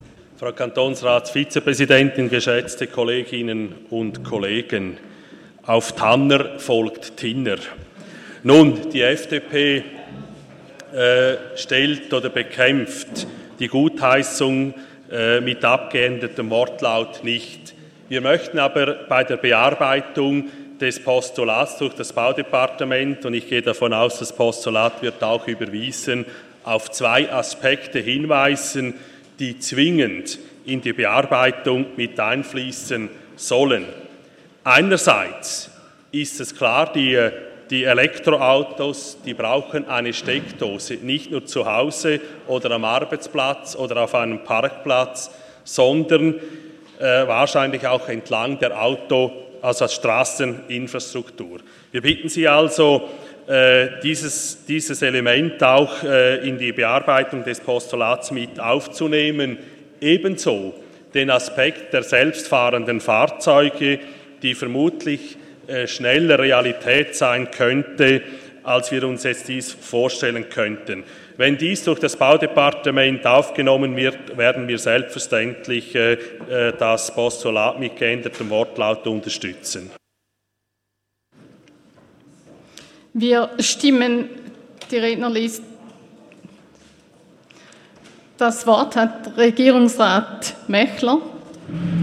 27.11.2017Wortmeldung
(im Namen der FDP-Fraktion): Der Antrag ...
Session des Kantonsrates vom 27. und 28. November 2017